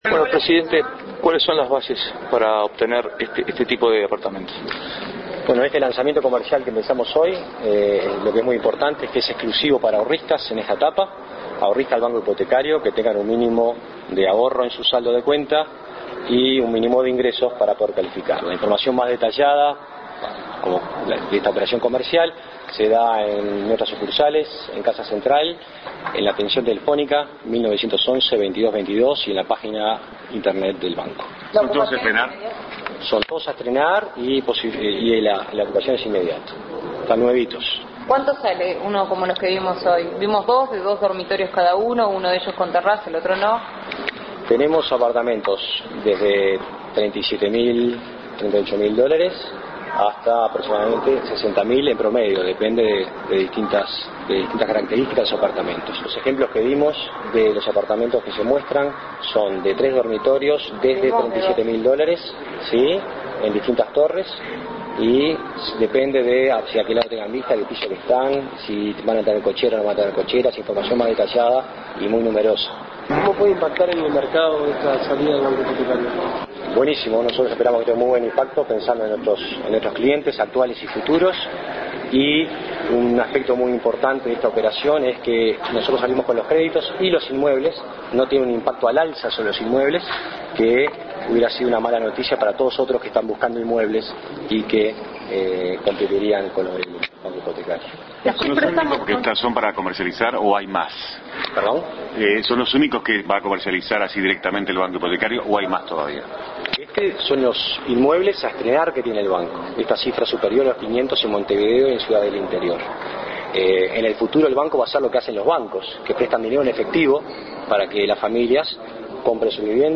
Declaraciones del Presidente del Banco Hipotecario del Uruguay, Jorge Polgar, luego de la conferencia de prensa del Directorio del BHU.